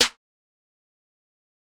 Metro Snare 11.wav